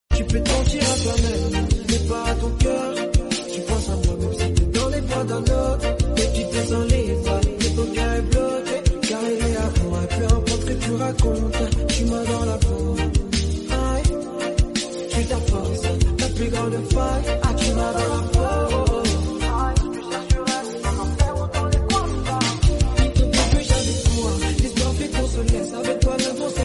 juakali Amplifier#sound installation